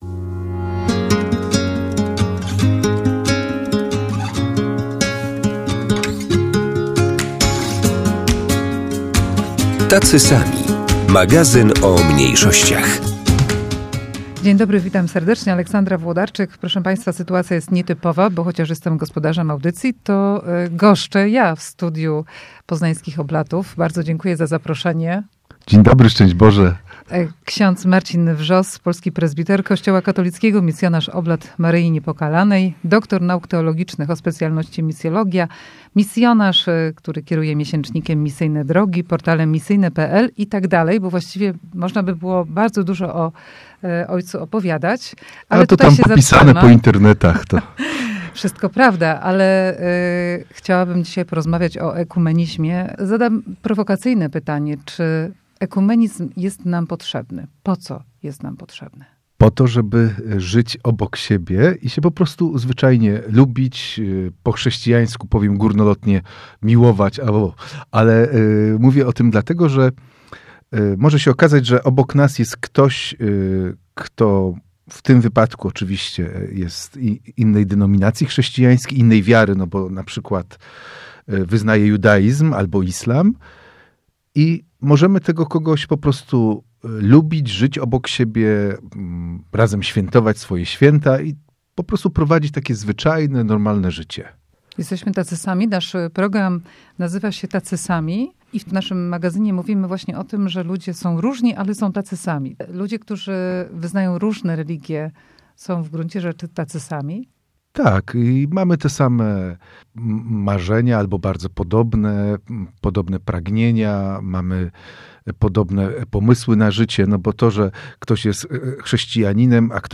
W magazynie "Tacy sami" dziś dyskusja z duchownym katolickim, misjonarzem i działaczem na rzecz dialogu międzyreligijnego w Poznaniu.